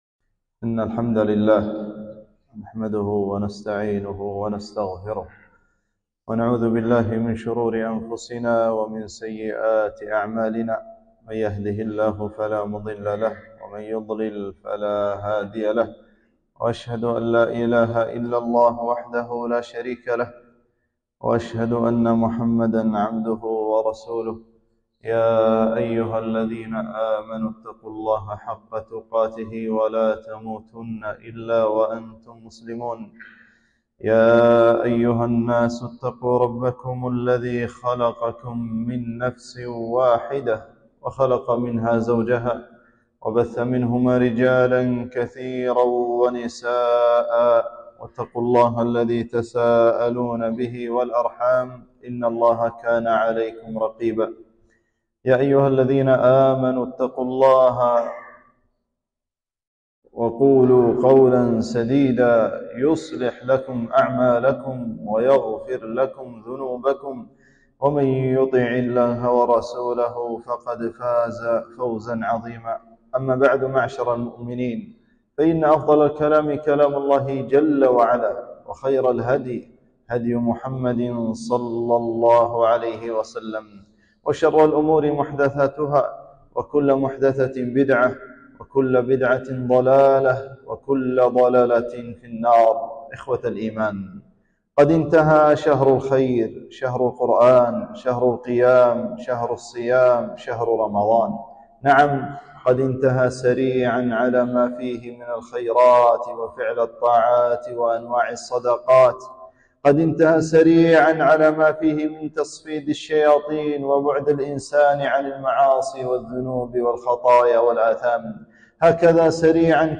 خطبة - ماذا بعد رمضان؟